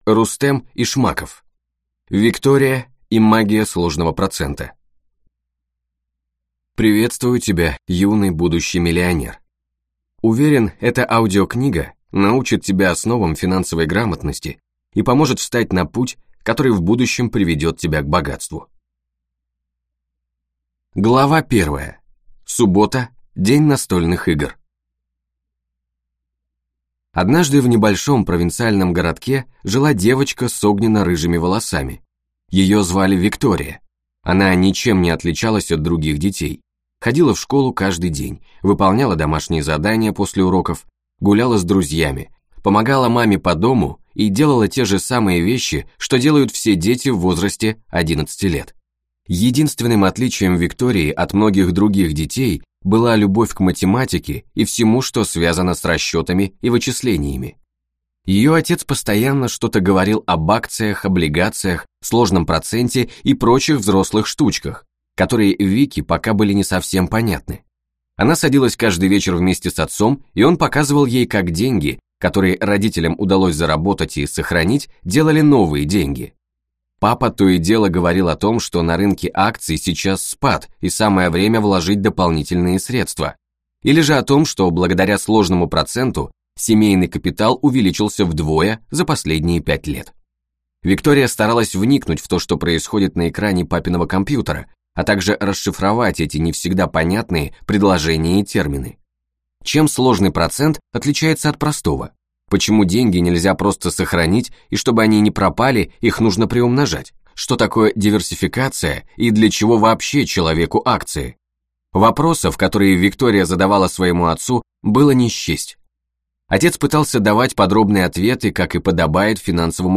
Аудиокнига Виктория и магия сложного процента | Библиотека аудиокниг